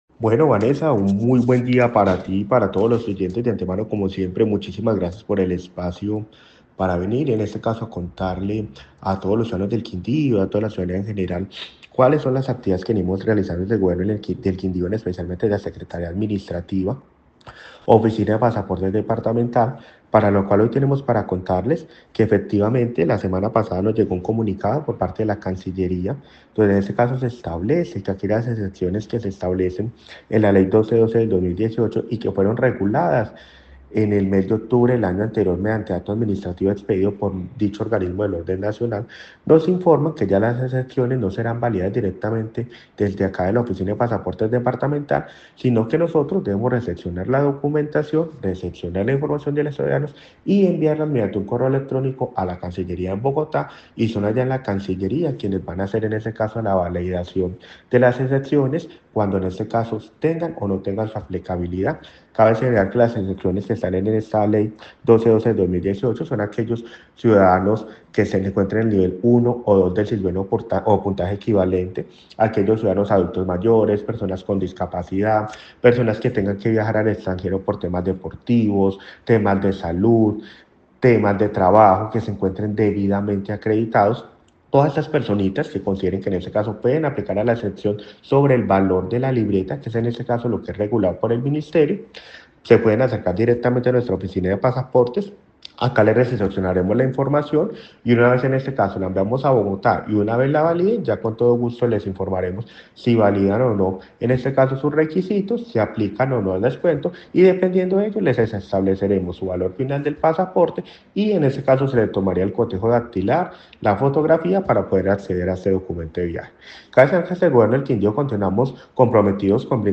Secretario administrativo de la gobernación